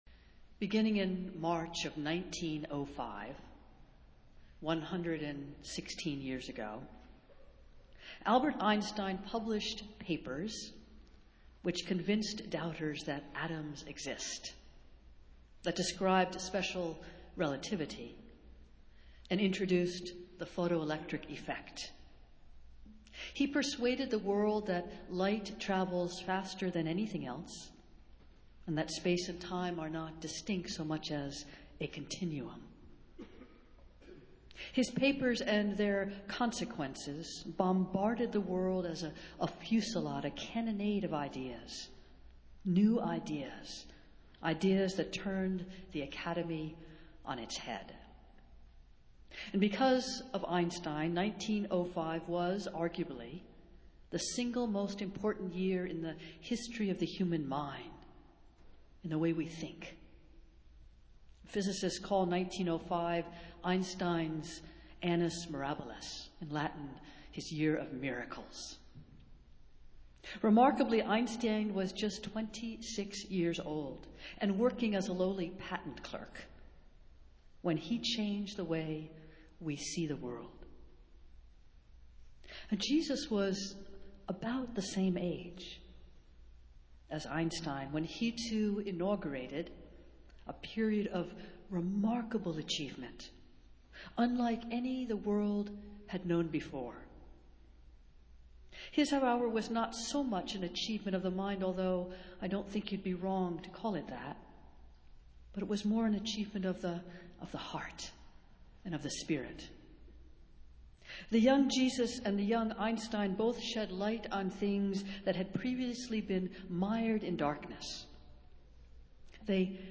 Festival Worship - Third Sunday after Epiphany